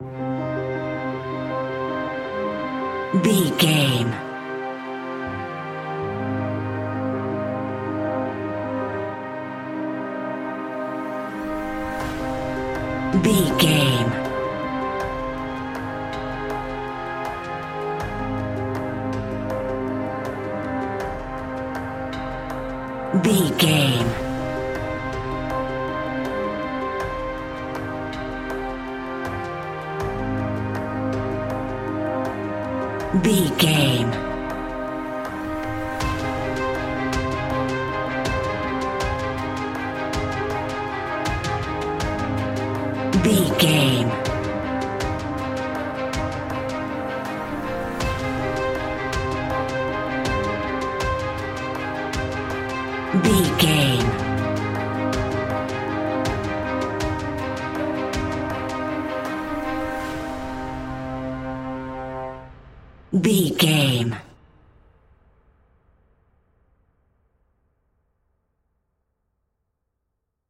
Aeolian/Minor
tension
ominous
dark
suspense
haunting
eerie
instrumentals